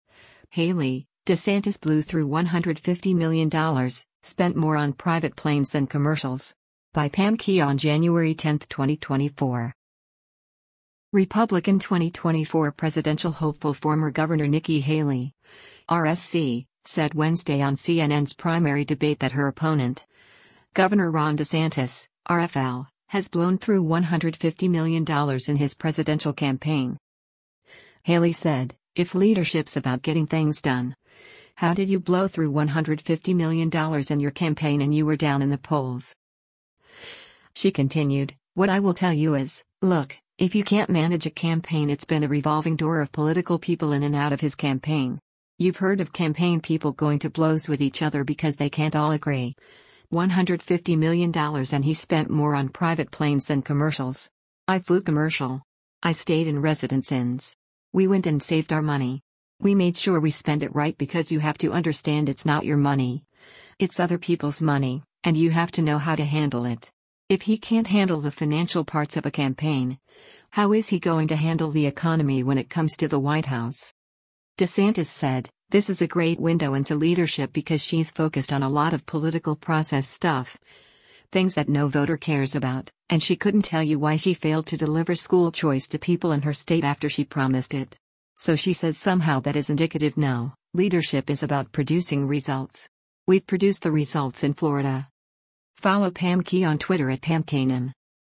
Republican 2024 presidential hopeful former Gov. Nikki Haley (R-SC) said Wednesday on CNN’s primary debate that her opponent, Gov. Ron DeSantis (R-FL), has blown through $150 million in his presidential campaign.